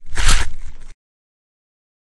Cut_sound.ogg